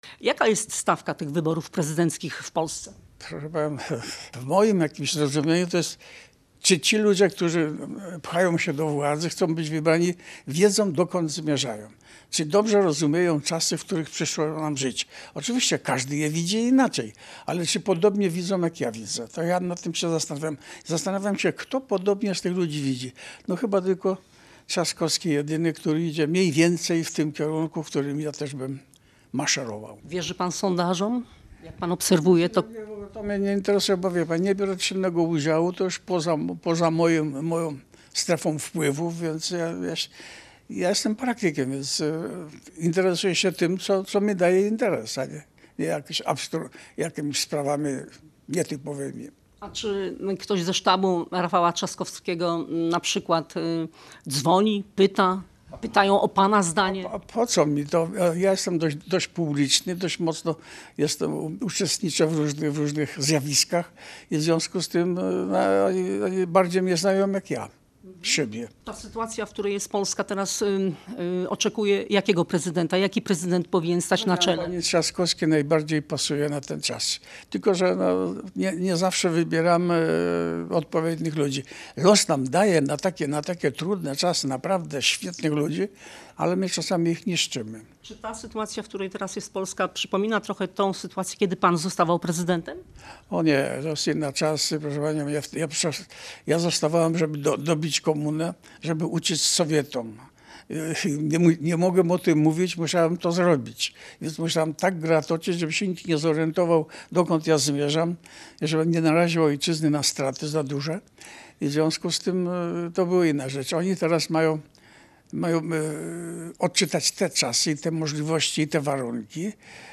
Lech Wałęsa w rozmowie z Radiem Gdańsk potwierdza, że w tegorocznych wyborach prezydenckich poprze Rafała Trzaskowskiego. Jego zdaniem polityk Koalicji Obywatelskiej to jedyny wartościowy kandydat w tej kampanii, który nie unika trudnych pytań dotyczących Polski.